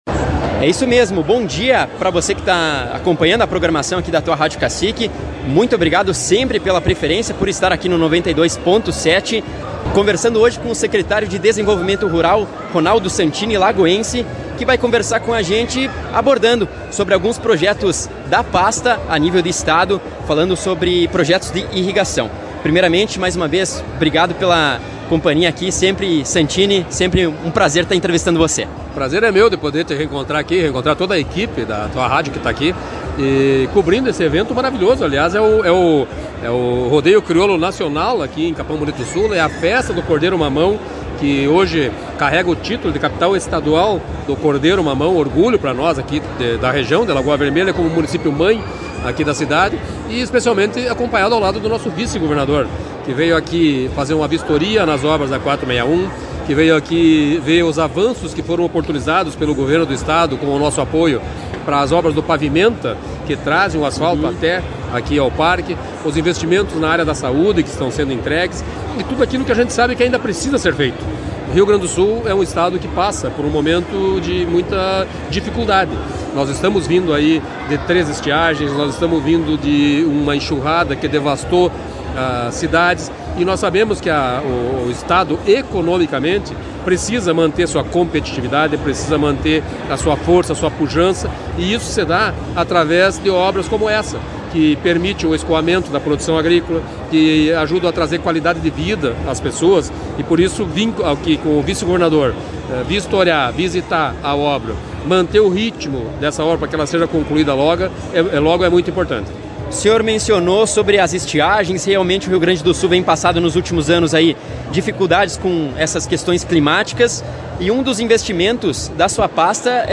Em entrevista com o secretário de Desenvolvimento Rural, Ronaldo Santini, foi comentado sobre as condutas para repensar o armazenamento de água.